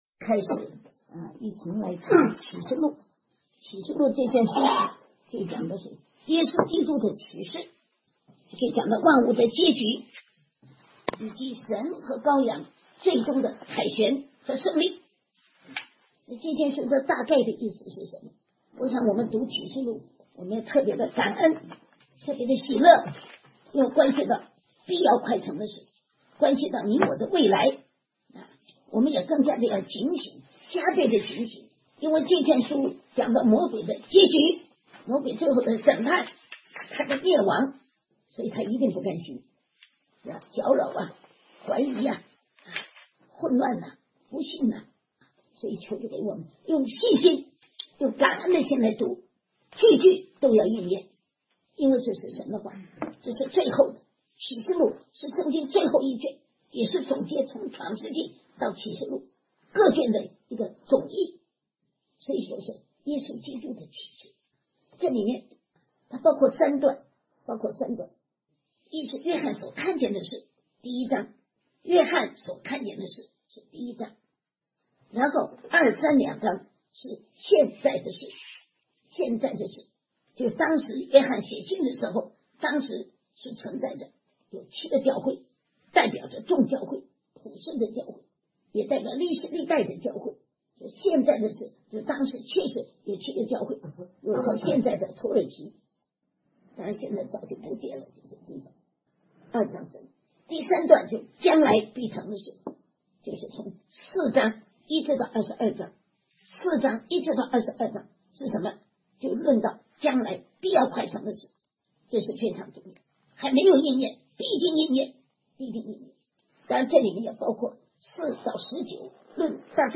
2016年讲道录音部分更新下载